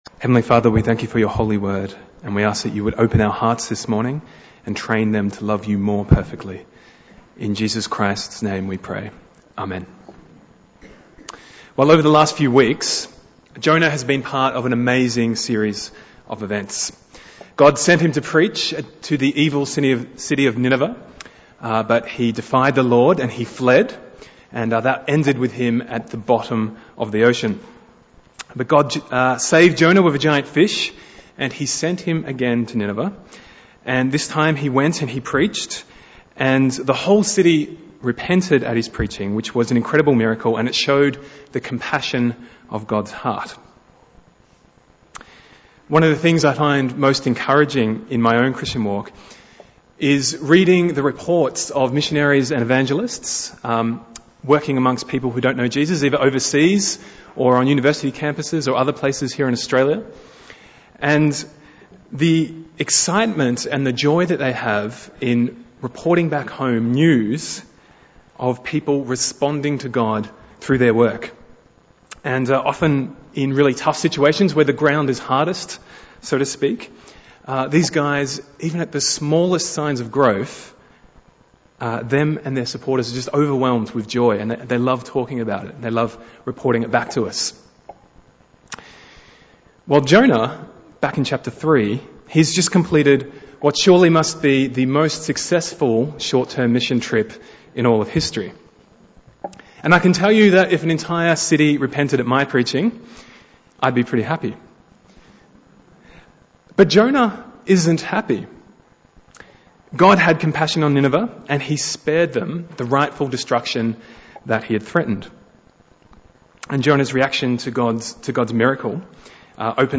Bible Text: Jonah 4:1-11 | Preacher